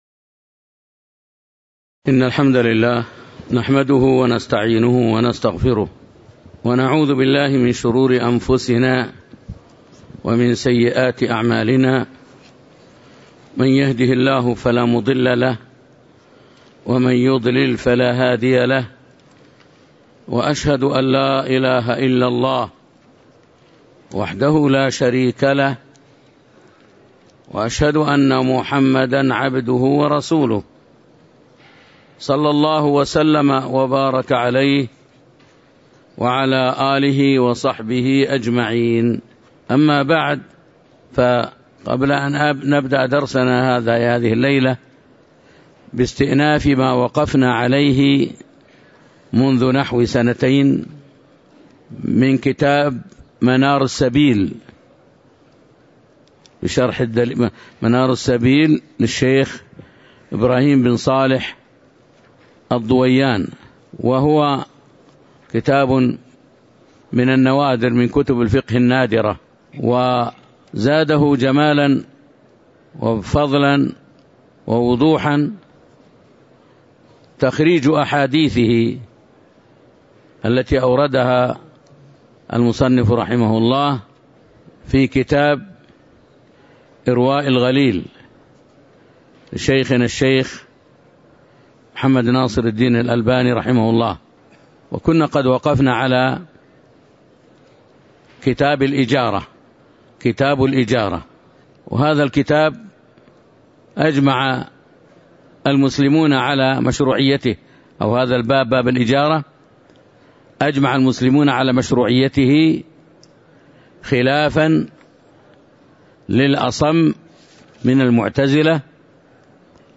تاريخ النشر ٢٨ رجب ١٤٤٣ هـ المكان: المسجد النبوي الشيخ